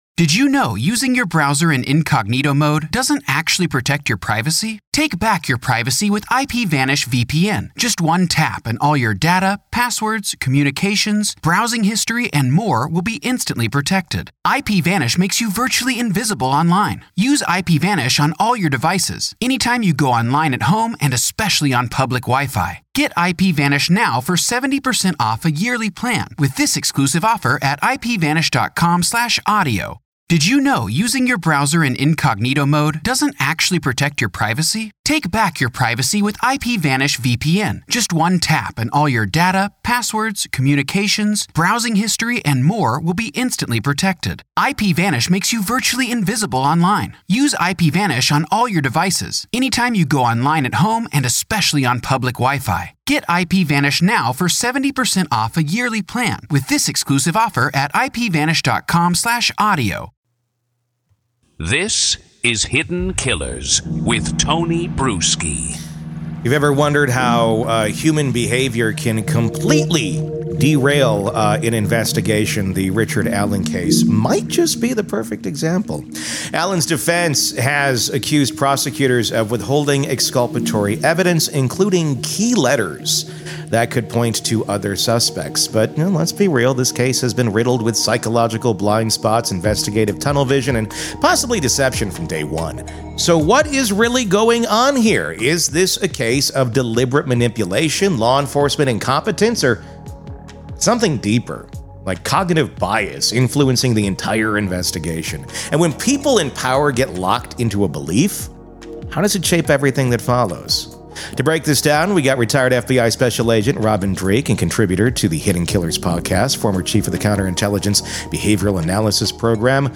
True Crime Today | Daily True Crime News & Interviews / Delphi Case Twist: Did Prosecutors “Willfully Ignore” Evidence That Could Clear Richard Allen?